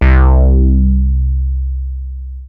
WARM MOOG 1.wav